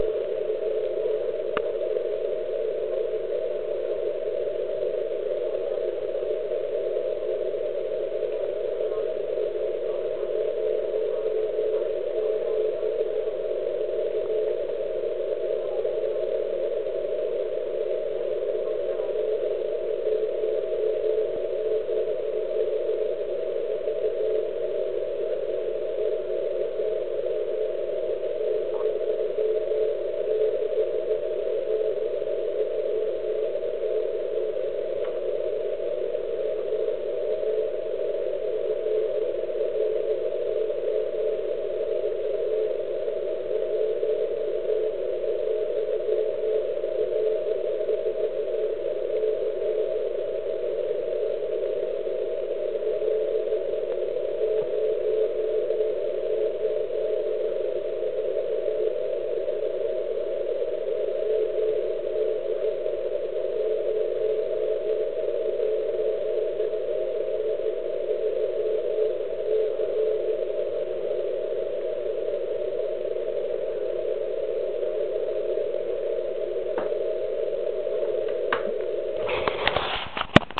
Pro Vaši orientaci uvádím, že se jedná o poslech majáku MIB (Micro Ionospheric Beacon). Ten pravidelně vysílá na kmitočtu 3579.74 kHz a slouží k předání WX informace z místa v Jizerských horách.
Nahrávka REC33 je dělána s použitým filtrem 300Hz a nahrávka REC34 je dělána s filtrem DSP označovaným jako APF (Audio Peak Filter).